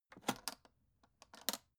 Royalty free sounds: Case